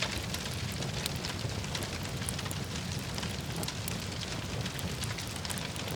fire-2.ogg